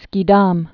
(skē-däm, sē-)